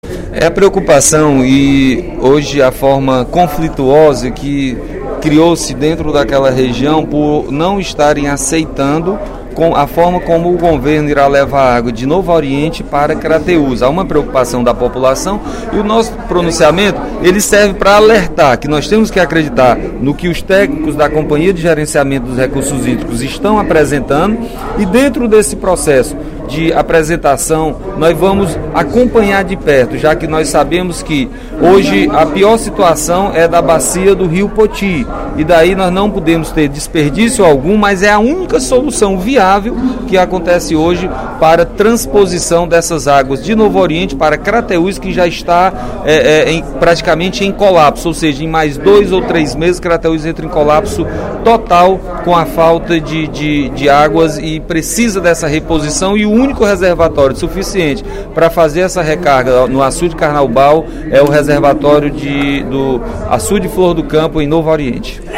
Durante o primeiro expediente da sessão plenária desta quinta-feira (13/06), o deputado Nenen Coelho (PSD) relatou o impasse que ocorre no sertão de Crateús para resolver o problema da falta d'água na região.